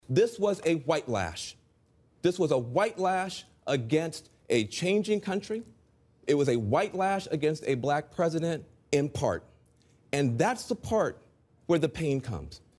According to CNN’s Van Jones, white racism explains the election of Donald Trump. Listen to his learned analysis from election night 2016 as the reality of Trump’s victory was starting to sink in.